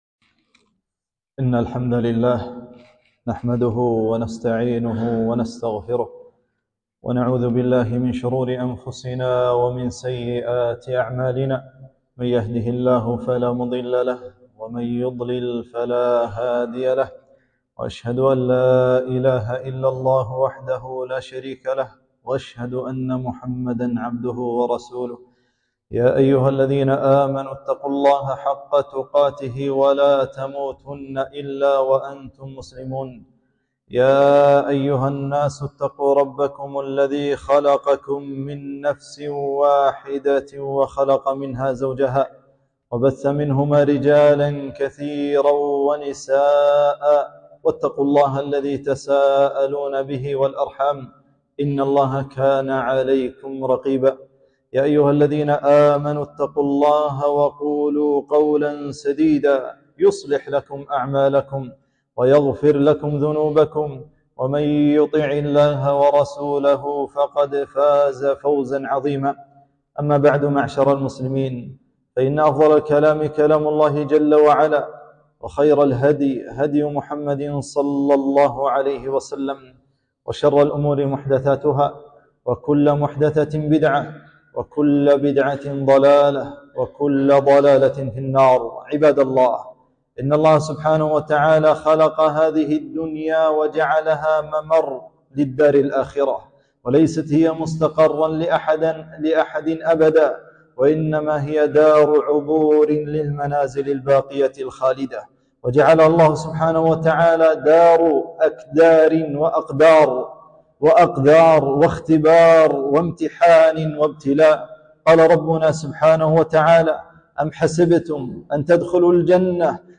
خطبة - فضل الصبر